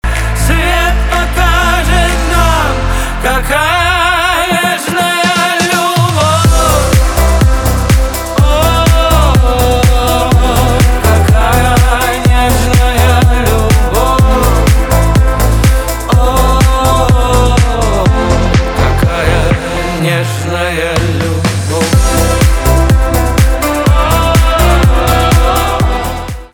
поп
битовые , басы
чувственные
романтические